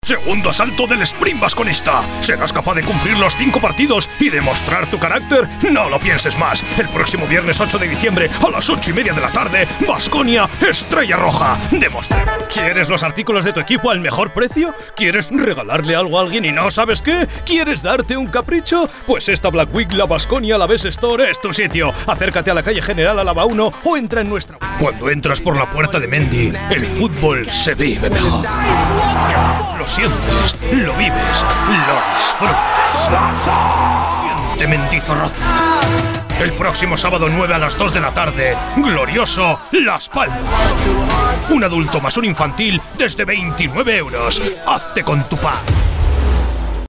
Précis
Articuler
Assuré